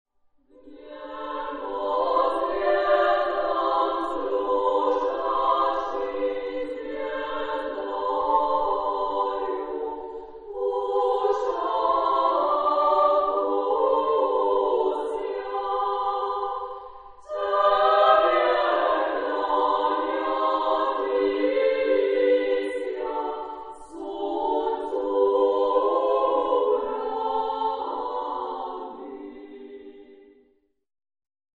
Genre-Style-Form: Christmas carol
Mood of the piece: simple ; moderate ; sincere
Type of Choir: SSA  (3 children OR women voices )
Tonality: A major